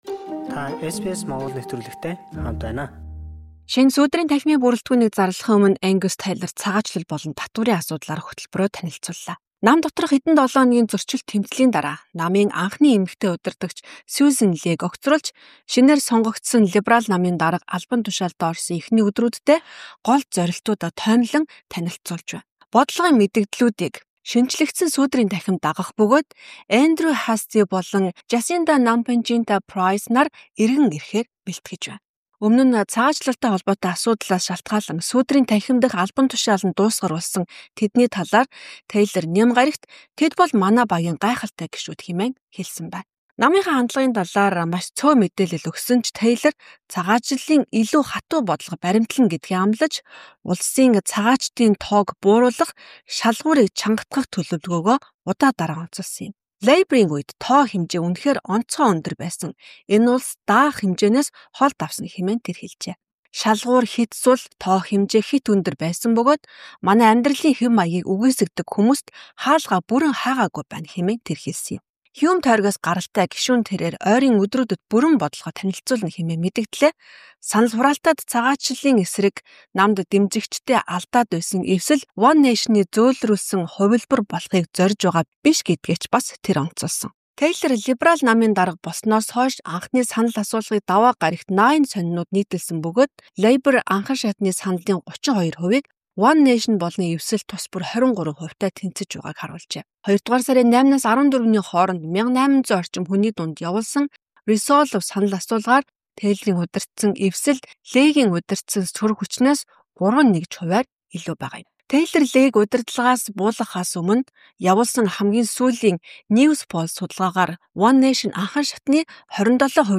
МЭДЭЭ: Цагаачлал ба татвар: Сөрөг хүчний шинэ удирдагч Ангус Тейлор бодлогоо танилцууллаа